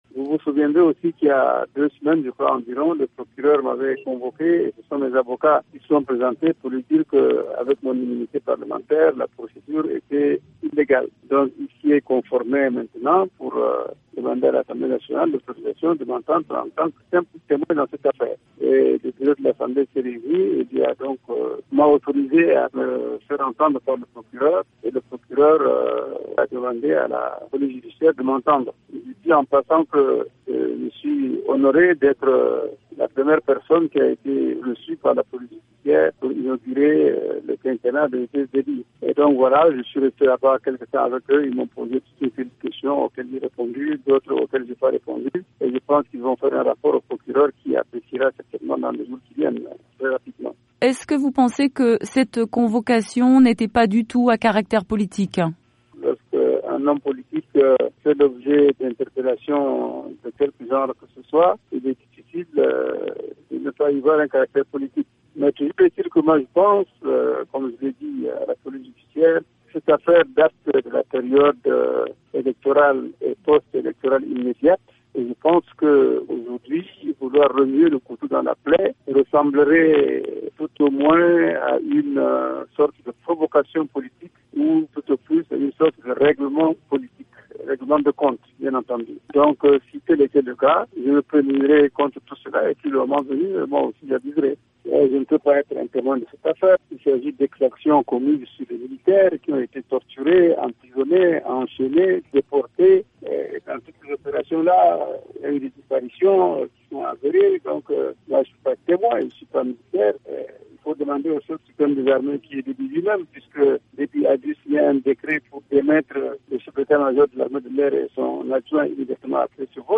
L'opposant tchadien Saleh Kebzabo joint à N'Djamena